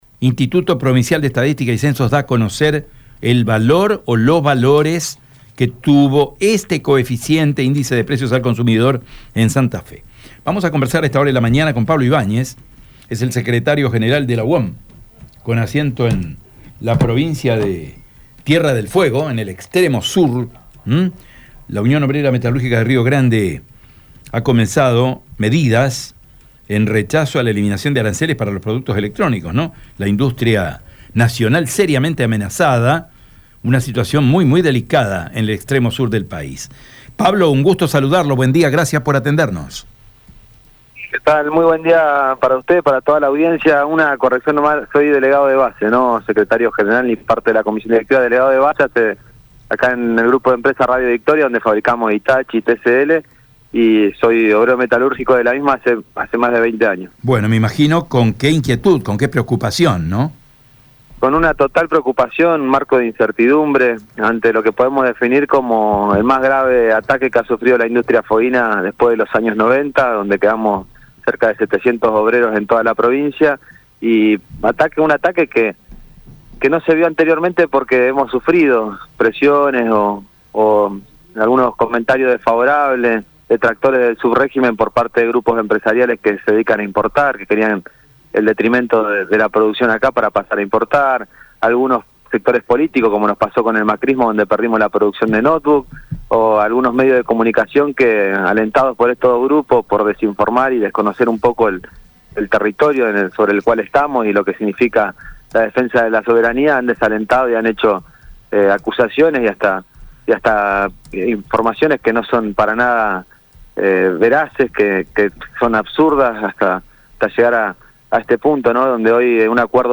en diálogo con EME Medios.